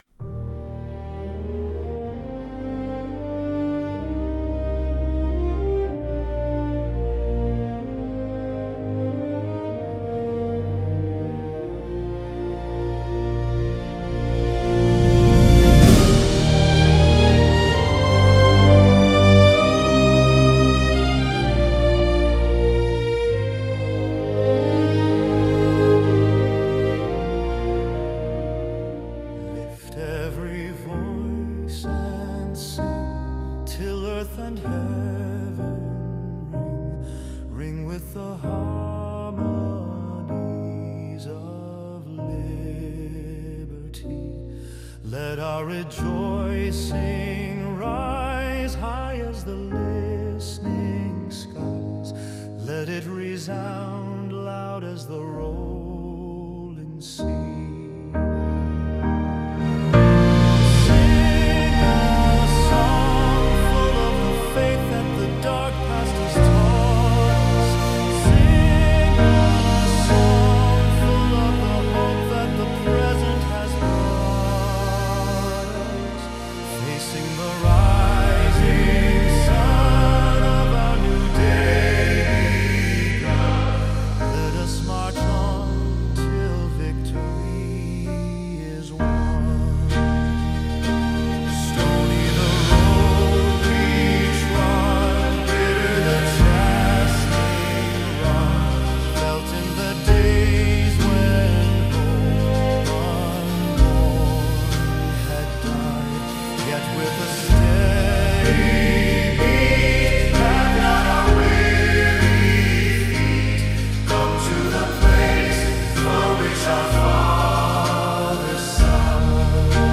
Instrumental (Reference)
Lift-Every-Voice-and-Sing-Instrumental.mp3